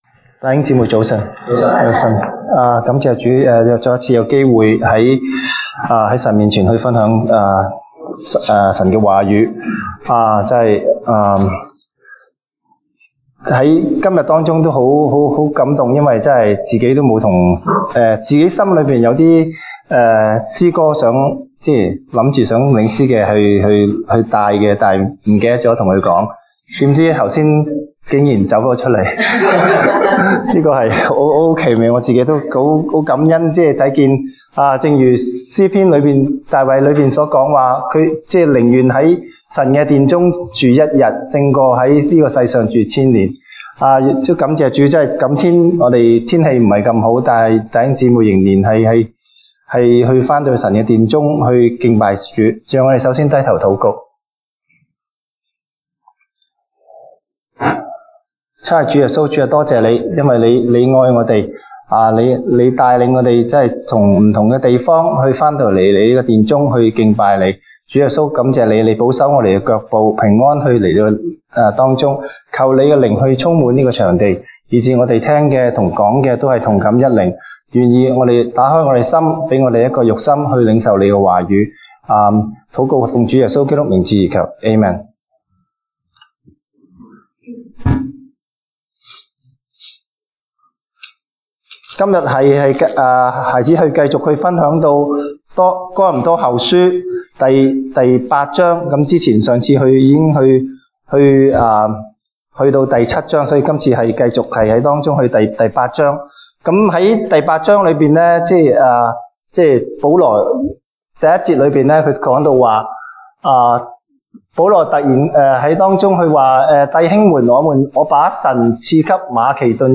東北堂證道 (粵語) North Side: 信靠順服